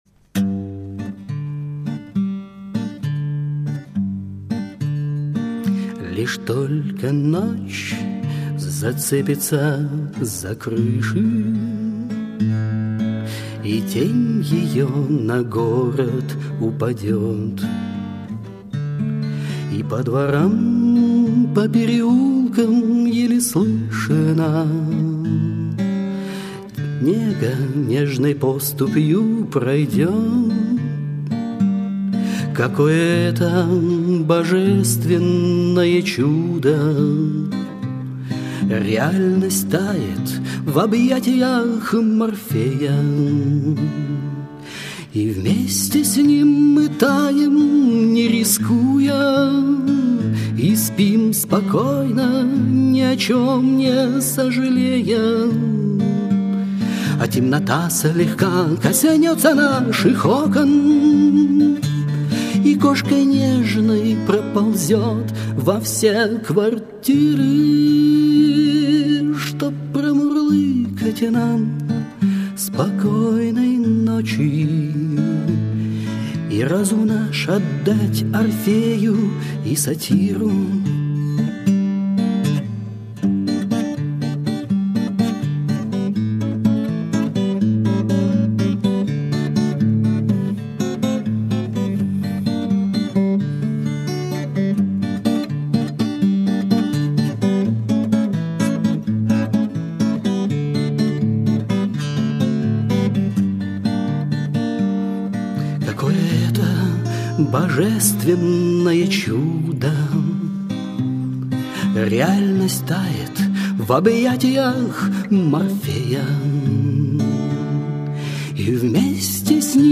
Армейские и дворовые песни под гитару